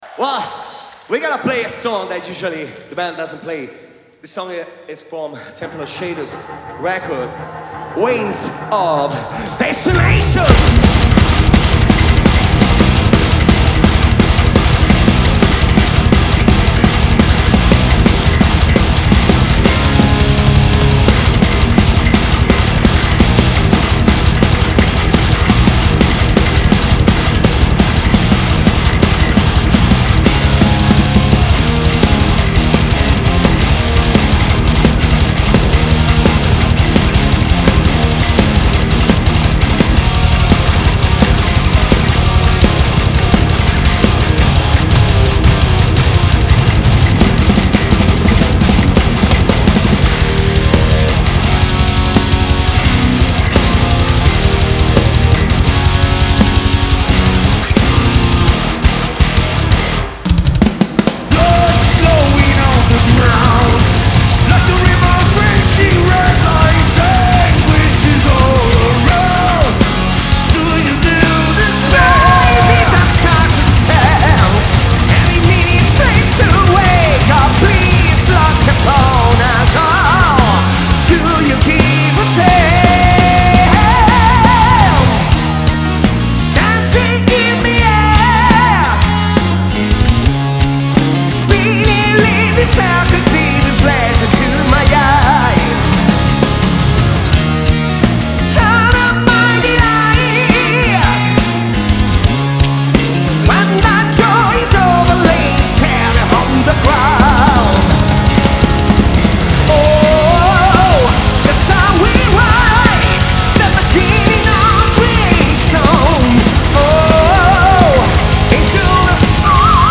Genre: Metal
Recorded at Fascination Street Studios.